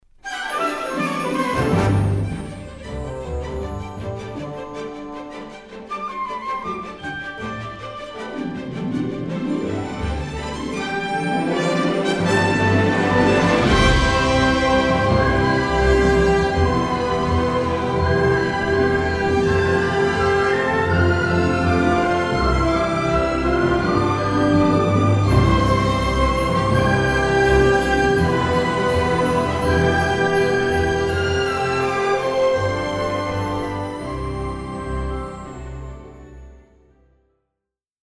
charming orchestral score